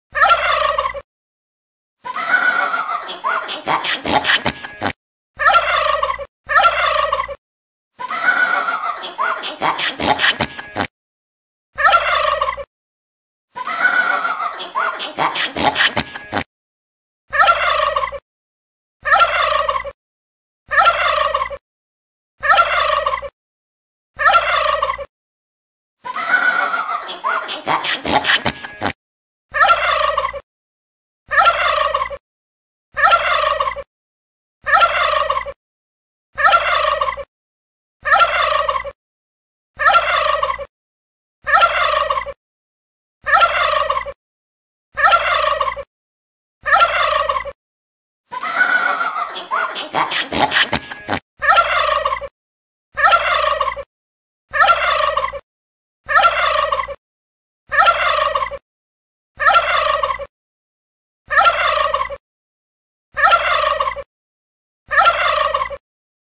Tiếng Gà Tây kêu
Thể loại: Tiếng vật nuôi
tieng-ga-tay-keu-www_tiengdong_com.mp3